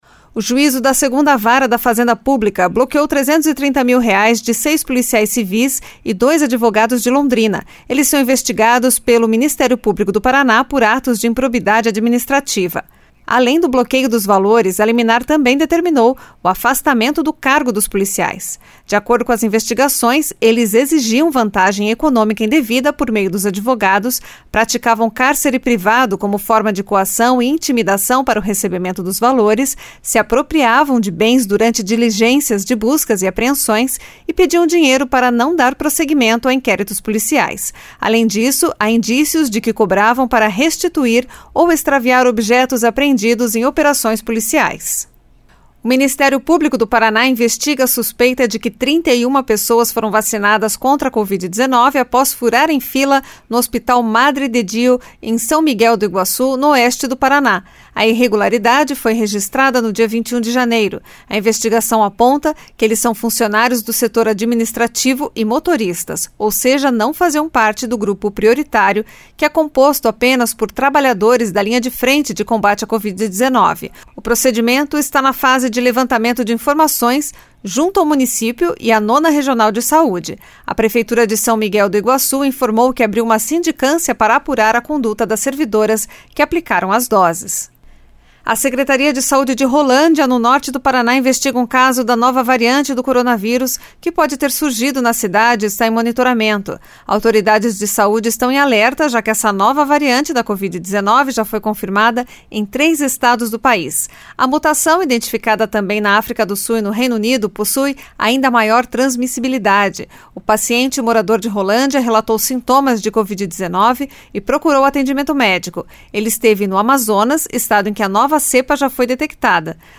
Giro de Notícias Tarde SEM TRILHA